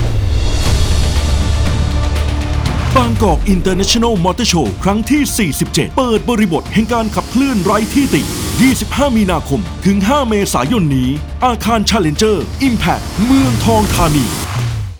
Radio Spot Motorshow47 15 วิ
spot-radio-15-วิ-Mts47.wav